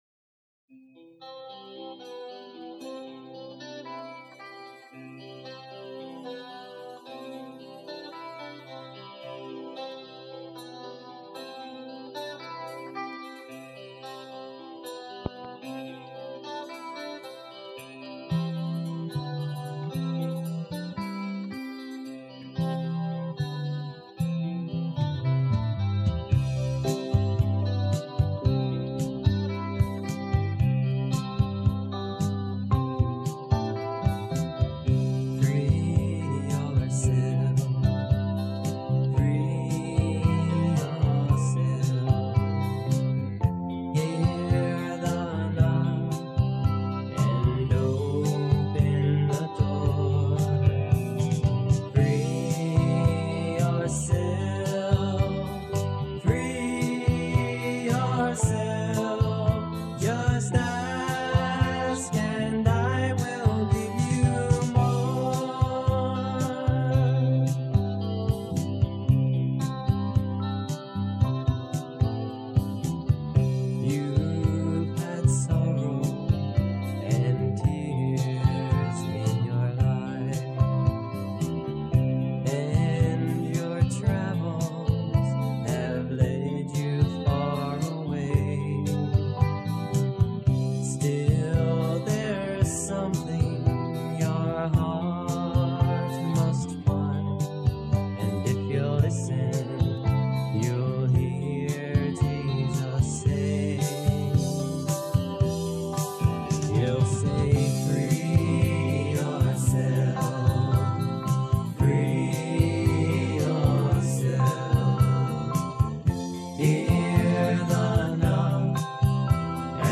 Synthesizer on all selections
Bass guitar on all selections
String and synthesizer arrangements on all selections
Drums and percussion on all selections